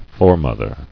[fore·moth·er]